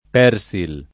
vai all'elenco alfabetico delle voci ingrandisci il carattere 100% rimpicciolisci il carattere stampa invia tramite posta elettronica codividi su Facebook persil [ p $ r S il ] (ted. Persil [ per @& il ]) s. m. — nome depositato